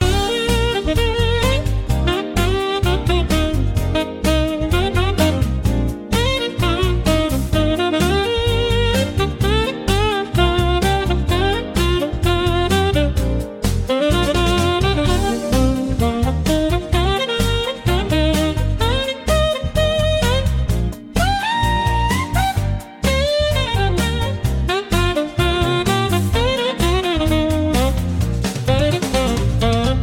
two instrumentals